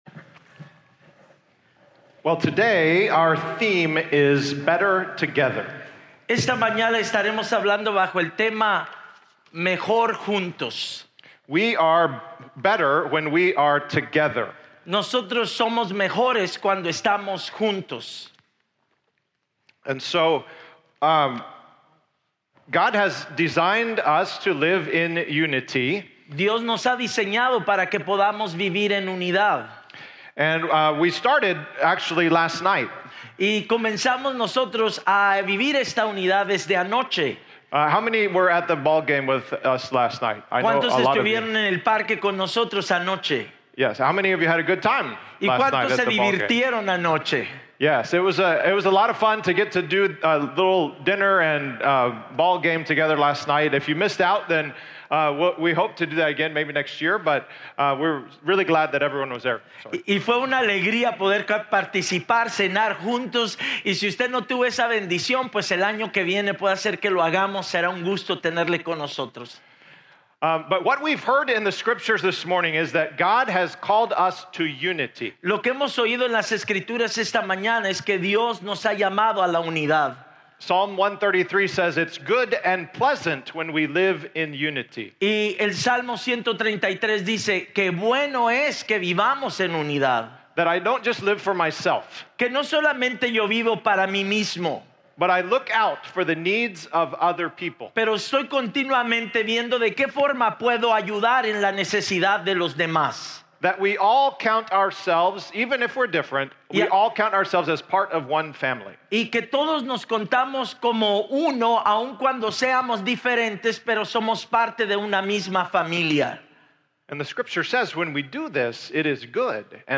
Joint service between First Presbyterian Church and Casa Nueva Voz.